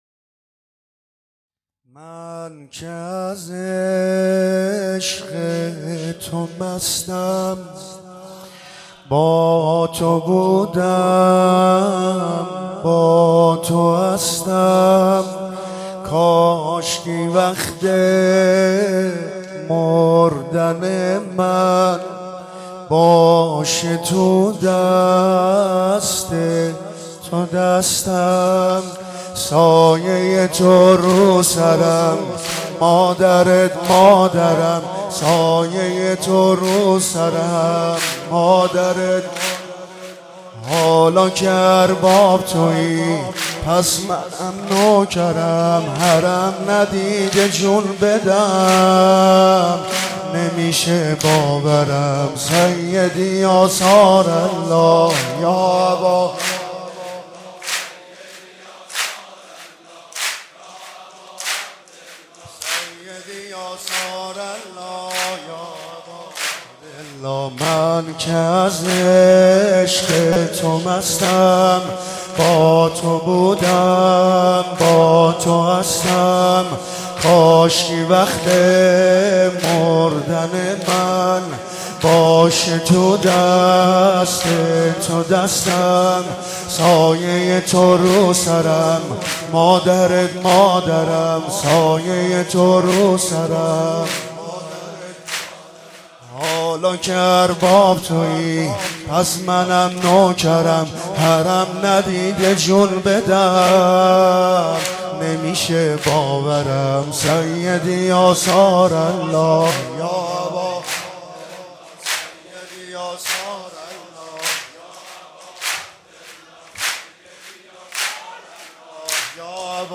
گلچین مداحی های ویژه ولادت حضرت علی اکبر(ع)